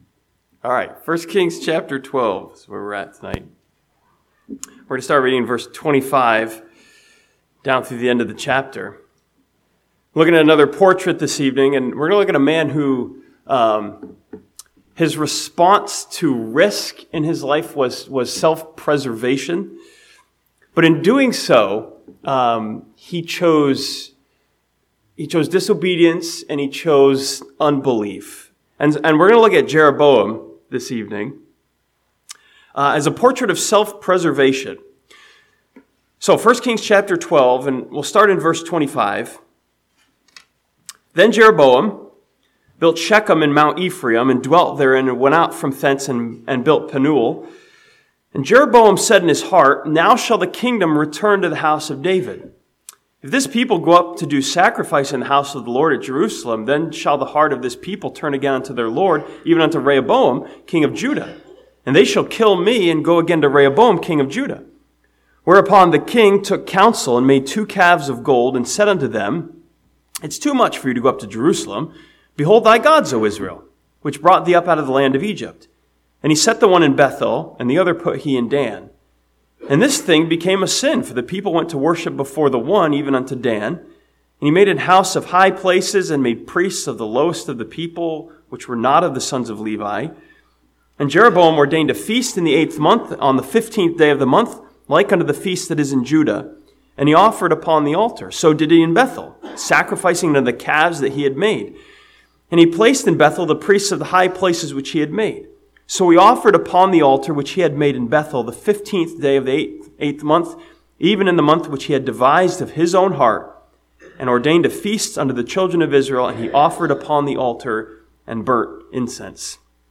This sermon from 1 Kings chapter 12 studies Jeroboam as a portrait of a man who chose self-preservation over faith and obedience.